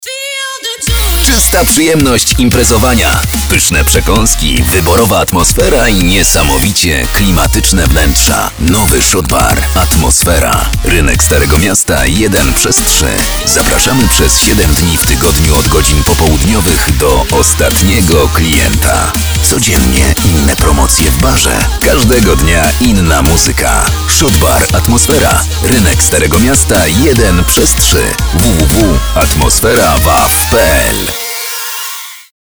Mężczyzna 30-50 lat
Doświadczony lektor dubbingowy.
Radiowy spot reklamowy